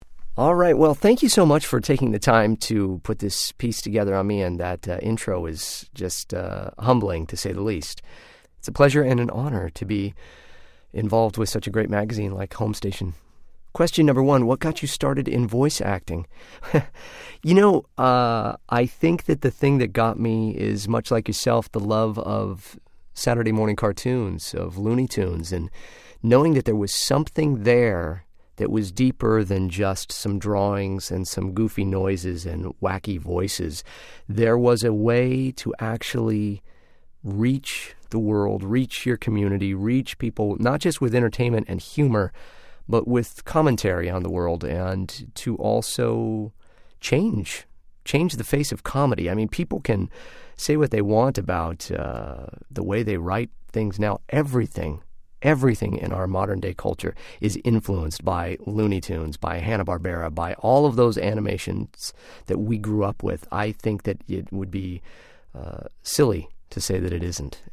The catch with a printed-word interview involving a voice actor is that part of the magic is in being able to listen to the responses — particularly one segment, where JAT goes off, rapid-fire, into a blizzard of different characters.